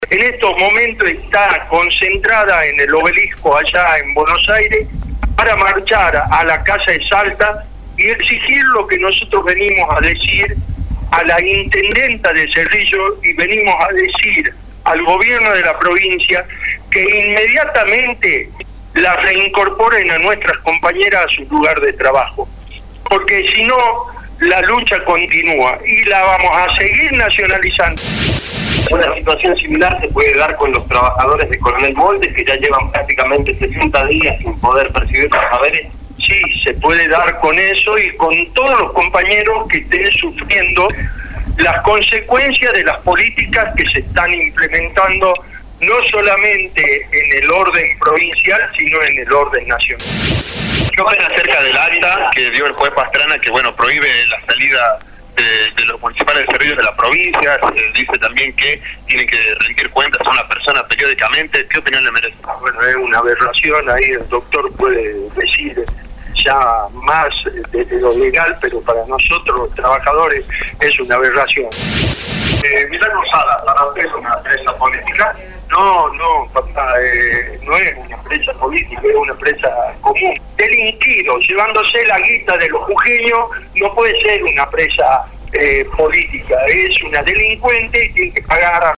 PERRO-SANTILLAN-RUEDA-DE-PRENSA.mp3